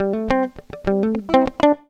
GTR 100 AM.wav